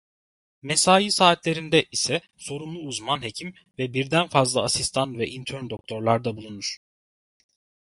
/ɑ.sis.tɑn/